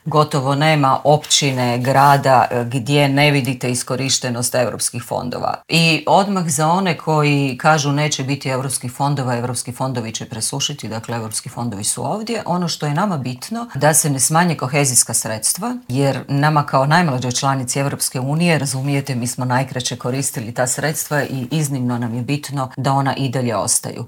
ZAGREB - Dok Europa pažljivo prati zbivanja na Bliskom istoku i poremećaje u opskrbi energentima, eurozastupnica Sunčana Glavak u Intervjuu Media servisa je poručila: "Vlada RH će i dalje pratiti cijene goriva."